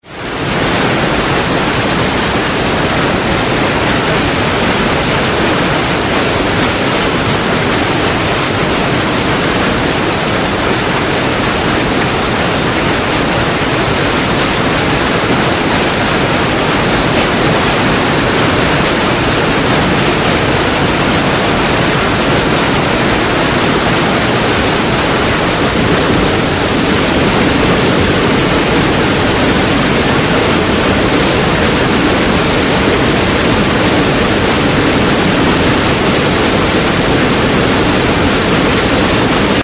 Шум разных водопадов для монтажа в mp3 формате
3. Шум большого водопада: